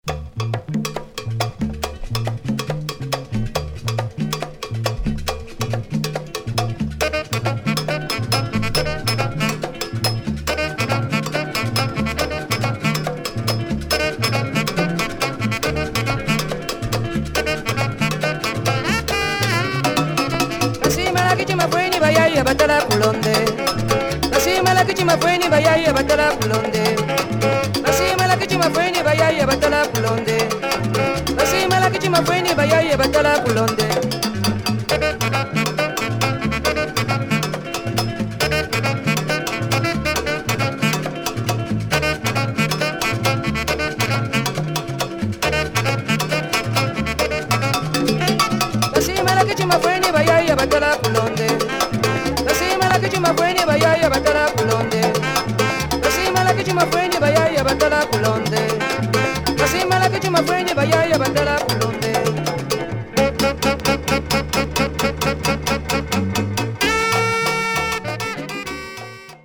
Superb tumbele album